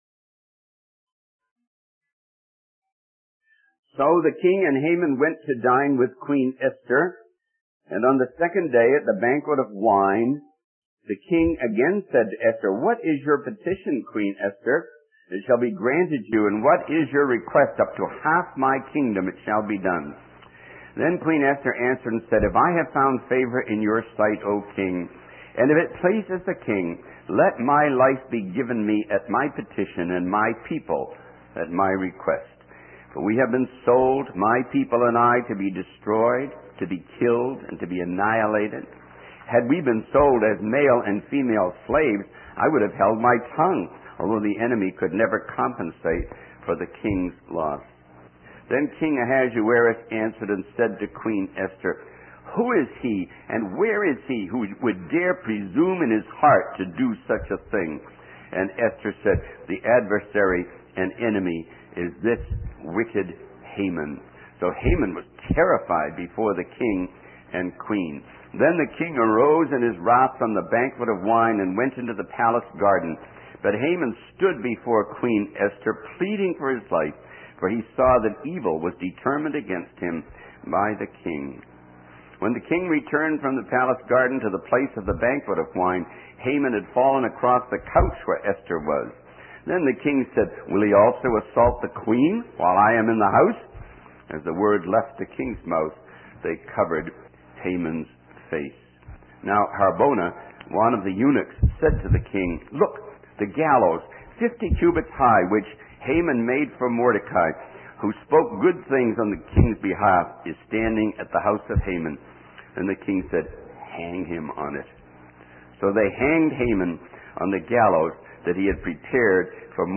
In this sermon, the speaker reflects on the book of Esther and highlights the hand of God in shaping the events of history for the benefit of His people. The speaker emphasizes that the Christian life is full of unexpected and significant moments, and encourages listeners to persevere and not give up.